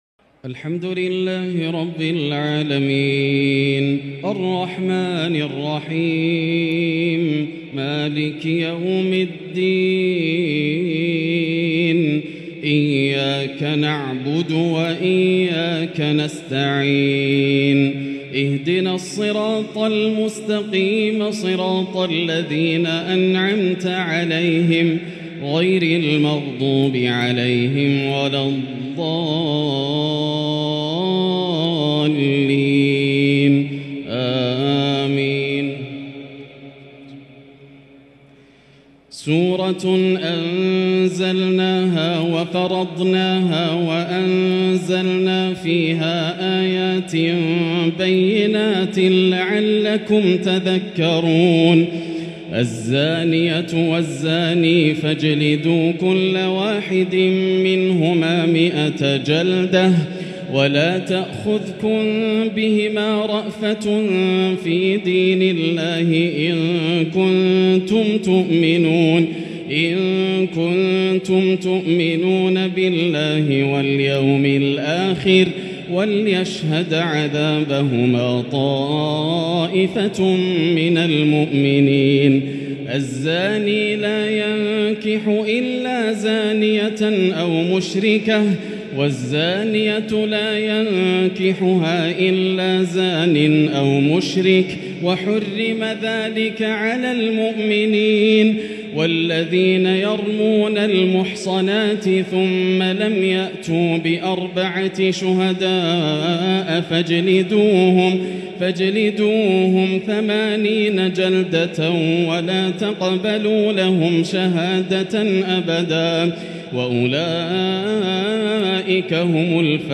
تهجد ليلة 22 رمضان 1444هـ | سورة النور كاملة | Tahajjud prayer | The night of Ramadan 22 1444H | Surah Nour > تراويح الحرم المكي عام 1444 🕋 > التراويح - تلاوات الحرمين